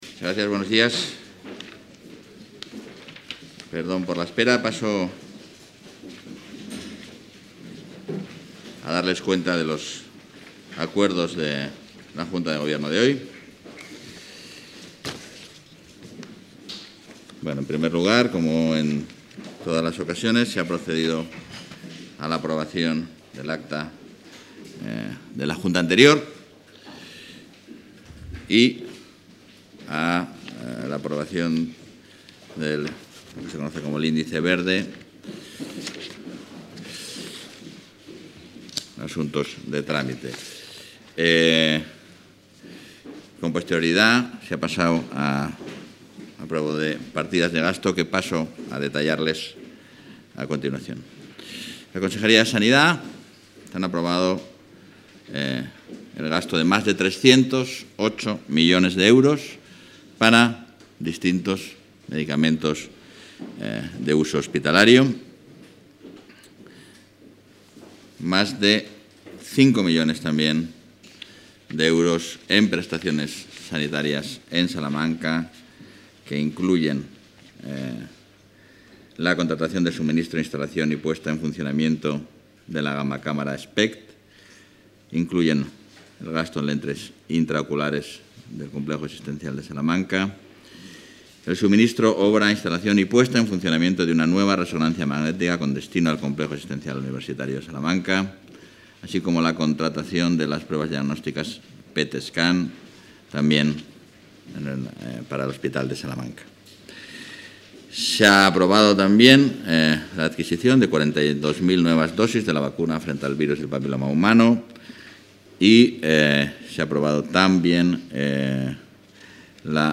Rueda de prensa tras Consejo de Gobierno.